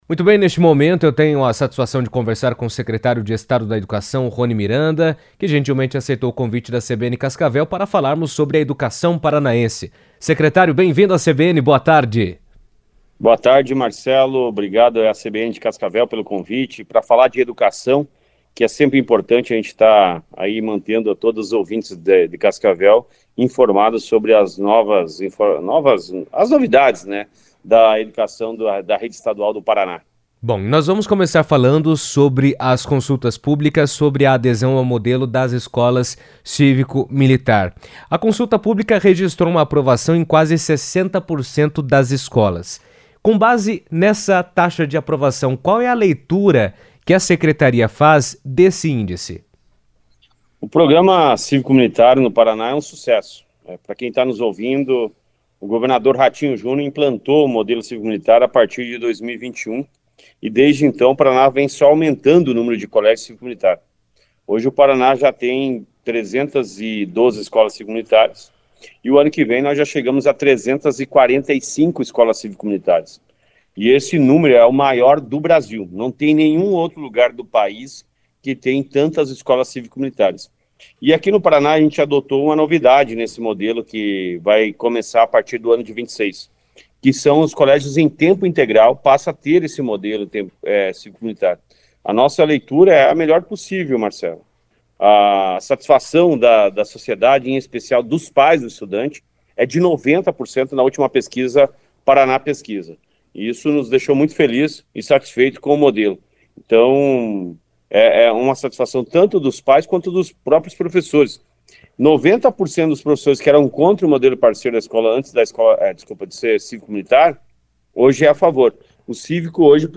A Secretaria da Educação também divulgou a lista de 14 escolas, em 11 municípios, que poderão participar do programa Parceiro da Escola no próximo ano. Roni Miranda, secretário de Estado da Educação, detalhou as mudanças e o calendário de implementação em entrevista à CBN.